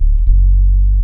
BASS 5 114-R.wav